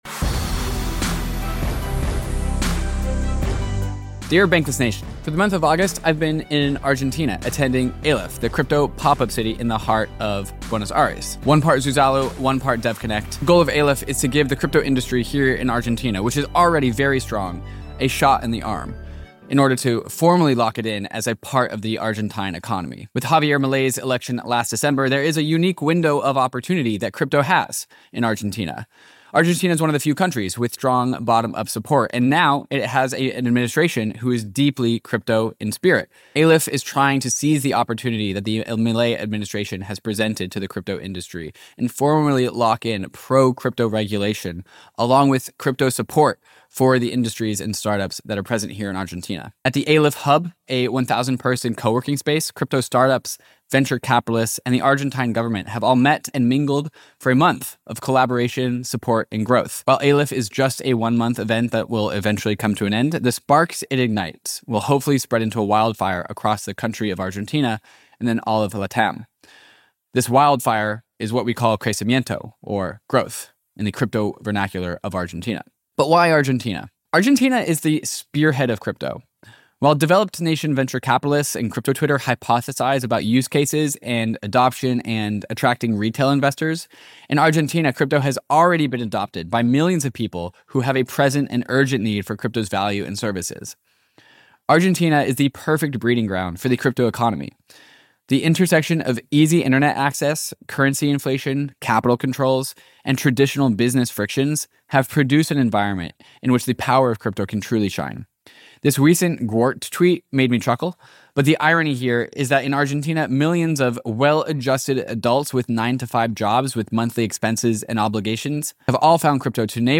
You’ll hear from three influential guests